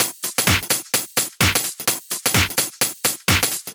Index of /VEE/VEE2 Loops 128BPM
VEE2 Electro Loop 015.wav